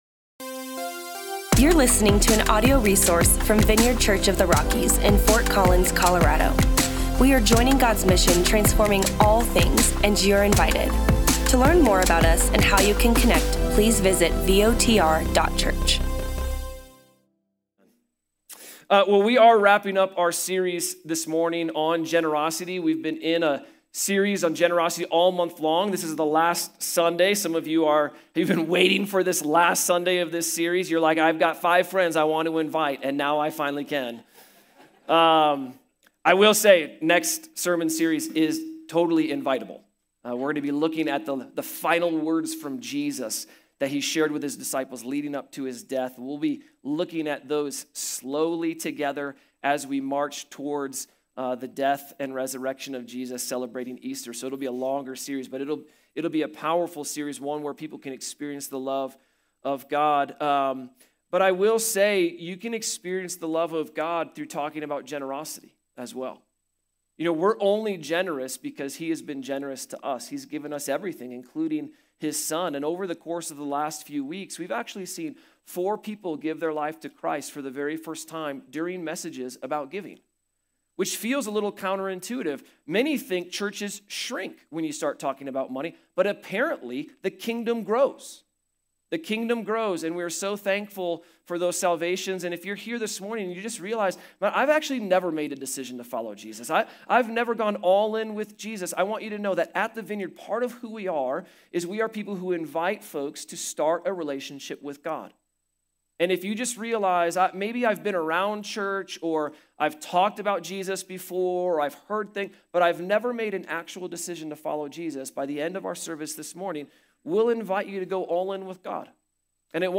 We finish our series on generosity with a panel interview from long-time members at the Vineyard. Listen in as we discover everyone’s journey towards generosity, the struggles, blessings, and real-life examples from decades of following Jesus.